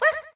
toadette_wah.ogg